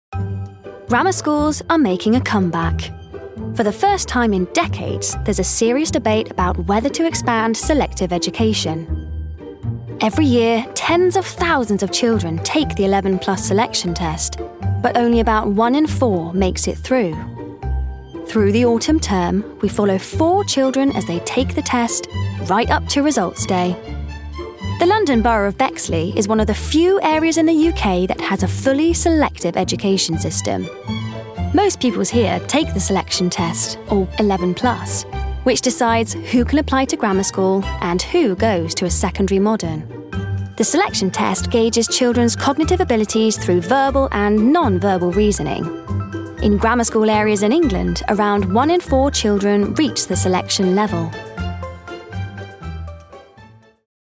Documentary Showreel
Female
Friendly